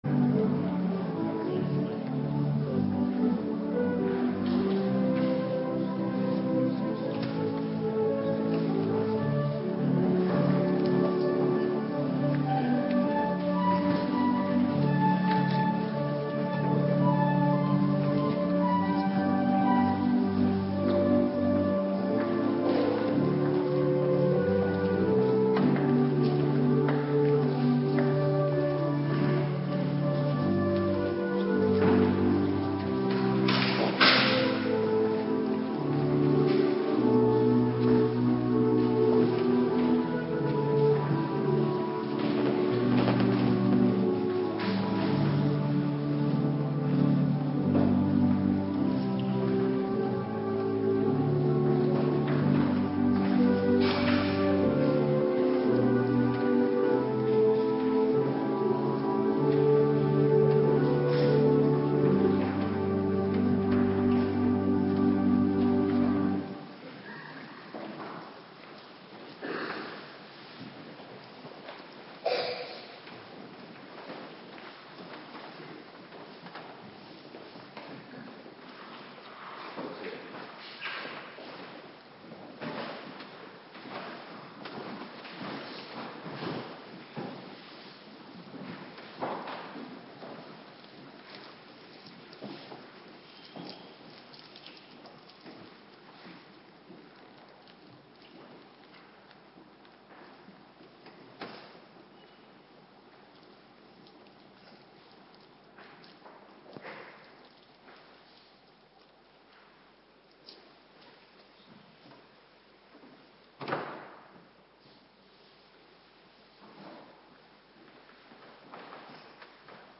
Morgendienst Voorbereiding Heilig Avondmaal - Cluster 1
Locatie: Hervormde Gemeente Waarder